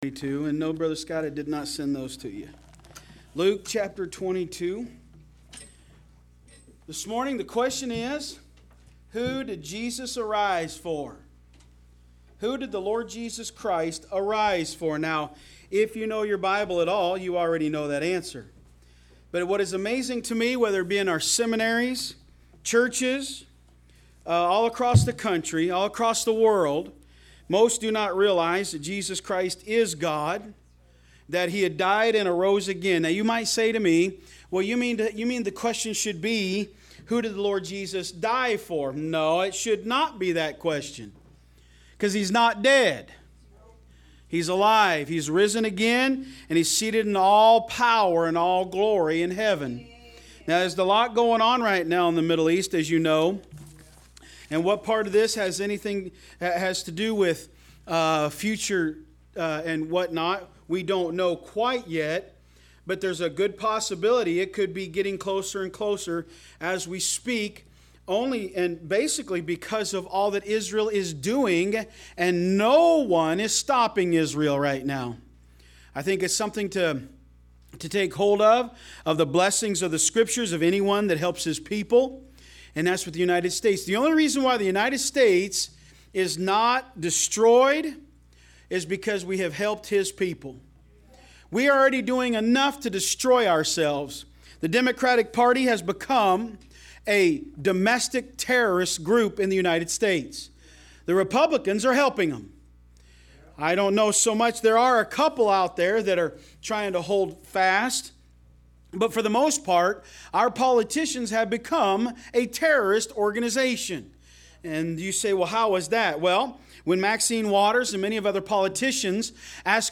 Online Sermons – Walker Baptist Church
From Series: "AM Service"